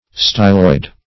Styloid \Sty"loid\, a. [Style + -oid: cf. F. stylo["i]de, Gr.